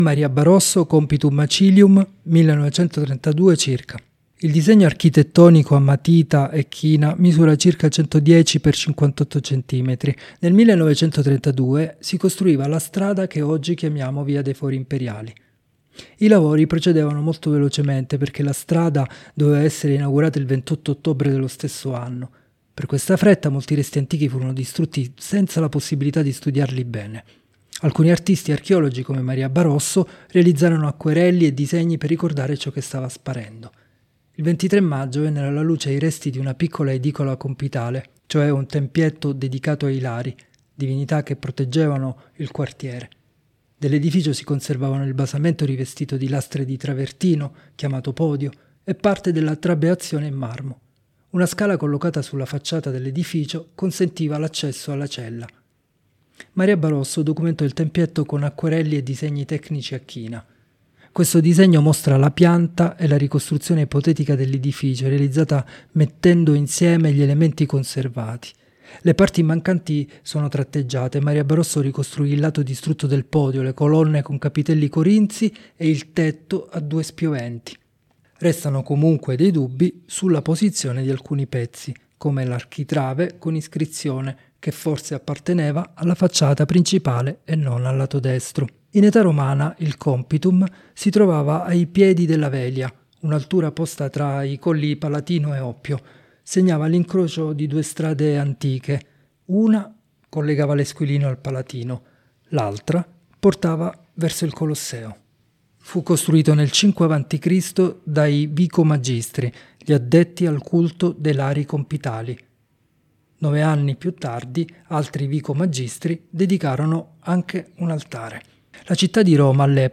Audiodescrizioni sensoriali opere selezionate: